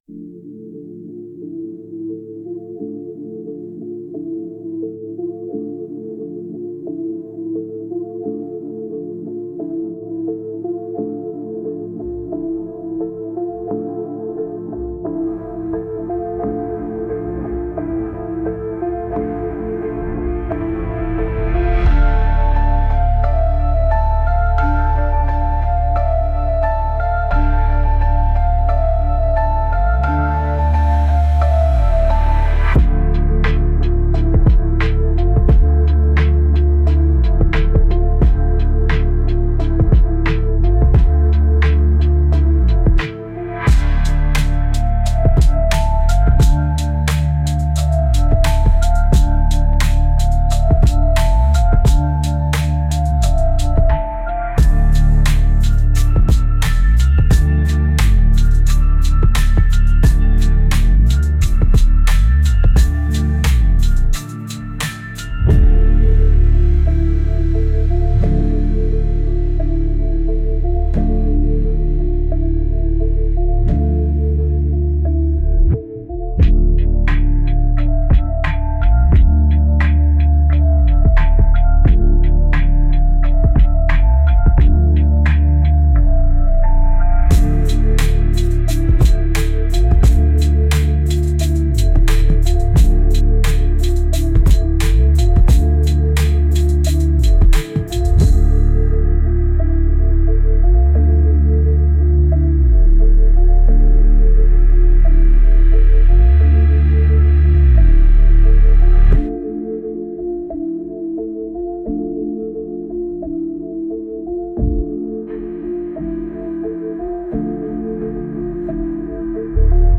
Instrumental - RLMradio Dot XYZ -2 mins